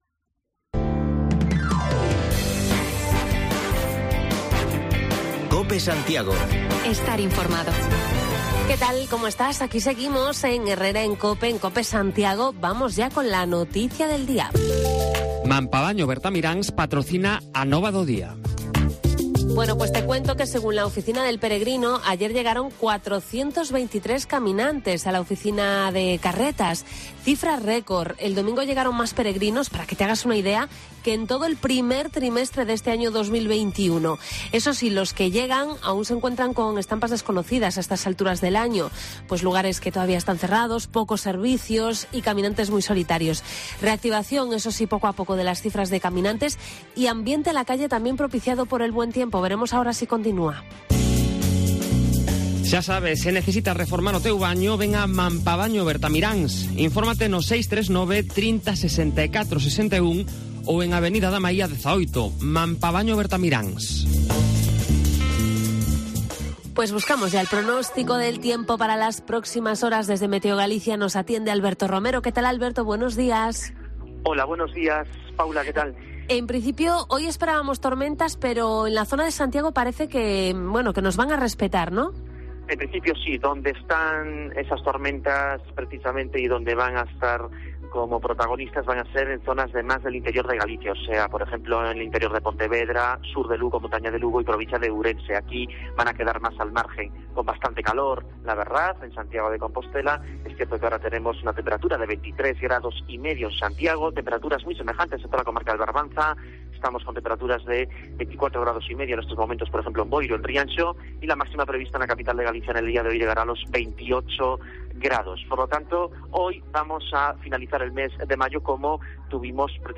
Actualizamos la última hora de este lunes, con parada en el Ensanche de Santiago para recoger la opinión de vecinos y comerciantes sobre el plan para la finca del antiguo colegio Peleteiro.